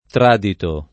tr#dito] agg. (lett. «tramandato dalla tradizione») — propr. (come si vede nelle costruz. con da...), part. pass. d’un teorico verbo it. riproducente il lat. tradere [tr#dere] «consegnare, tramandare»: caso simile a quello di edito — quasi d’uso generale l’acc. scr. (a scanso d’equivoci col part. pass. di tradire): il testo tràdito; la lezione tràdita dal codice A